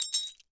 GLASS_Fragment_16_mono.wav